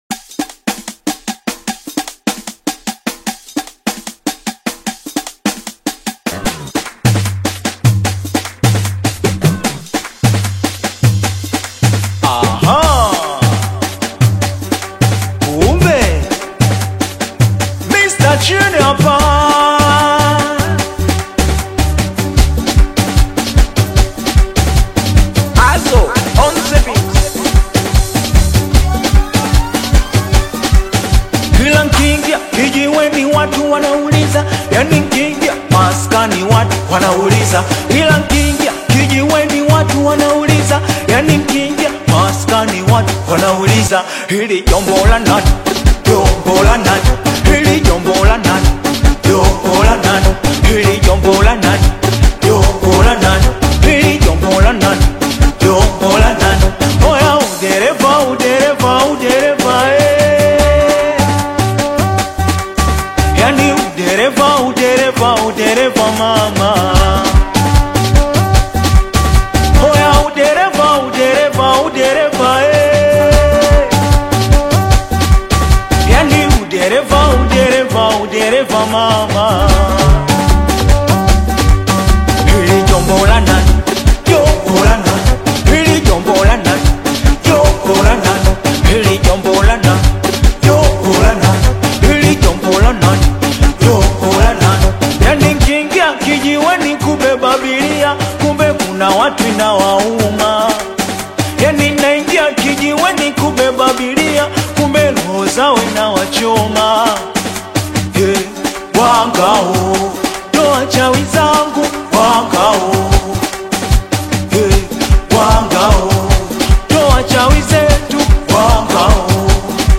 Tanzanian Singeli/Bongo Flava single